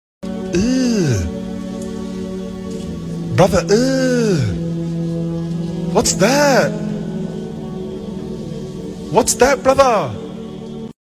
Eww brother eww Meme sound effects free download